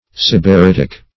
Sybaritic \Syb`a*rit"ic\, Sybaritical \Syb`a*rit"ic*al\, a. [L.